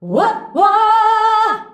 UAH-UAAH A.wav